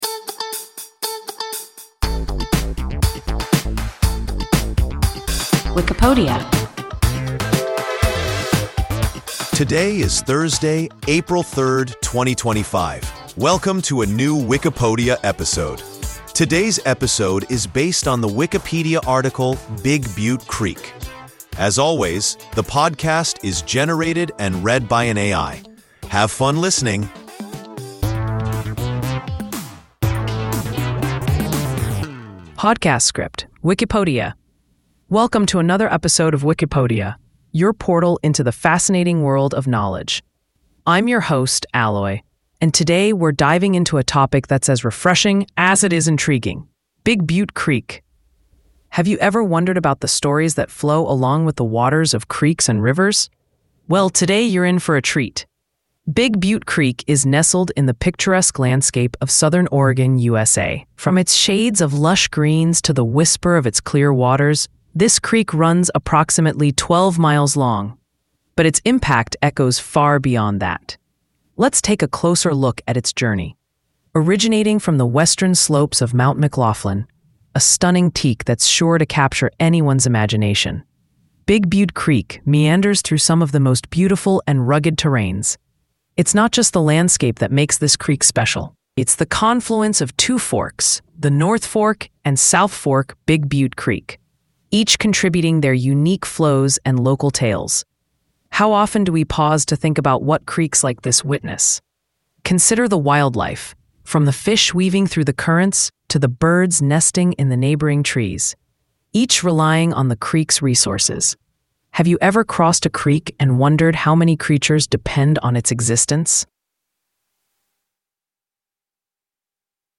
Big Butte Creek – WIKIPODIA – ein KI Podcast